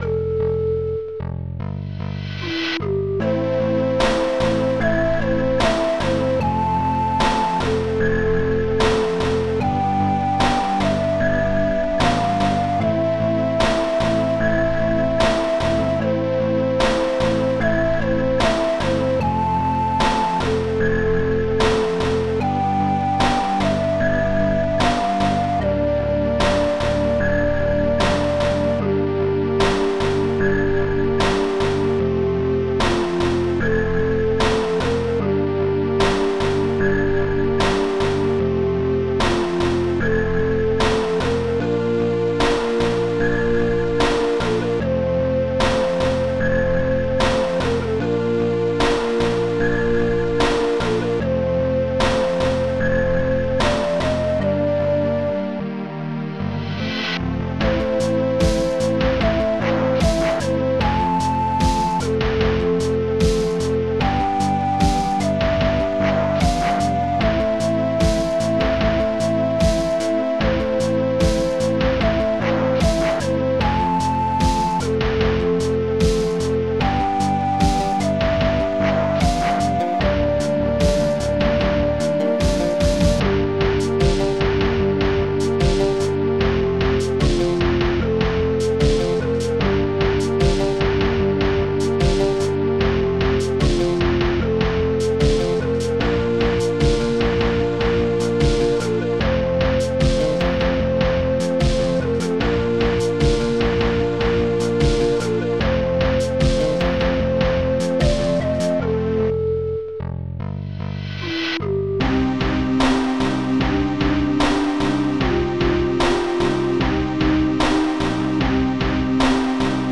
ST-19:Dethnicwinds
st-11:bluesnare
ST-19:Dwarmpad3
ST-19:Dtecnobass
ST-09:zzhihat